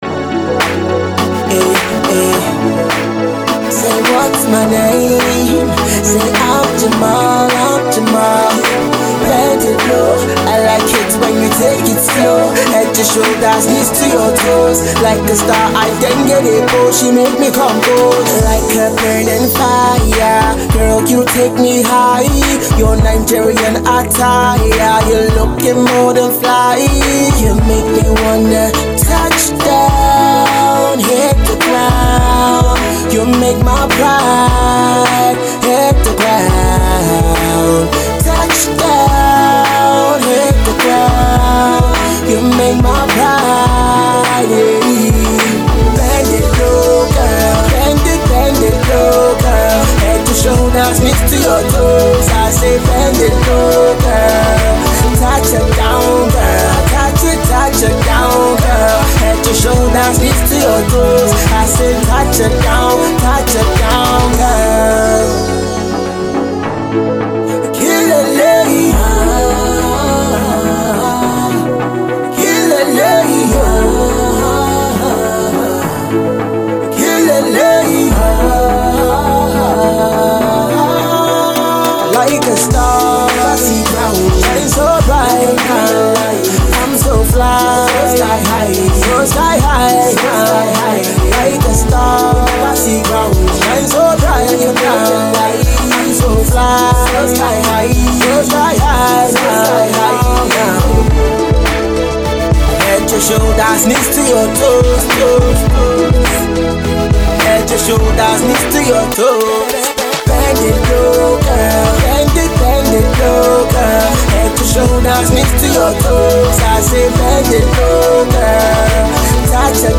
Afro Fusion R&B single